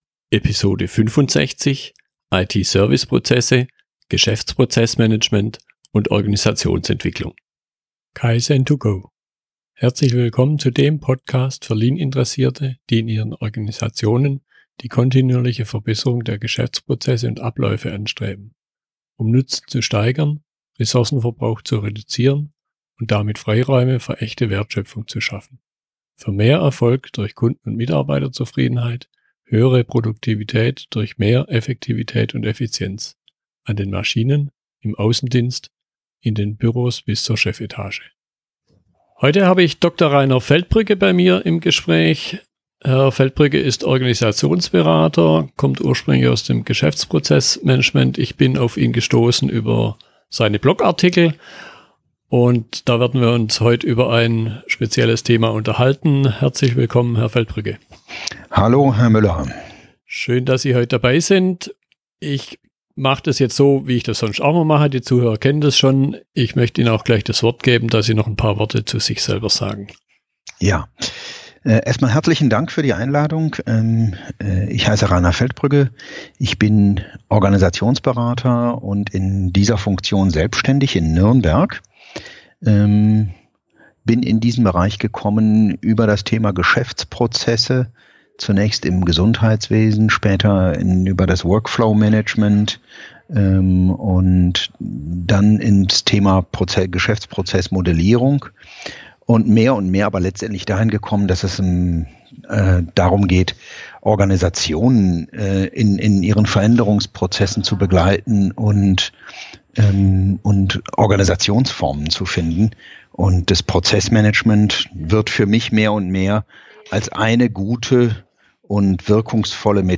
Wir unterhalten uns darüber, warum Geschäftsprozessmanagement manchmal nicht funktioniert, Prozesse nicht eingehalten werden und welche Abhilfe Organisationsentwicklung dabei leisten kann und was dazu nötig ist.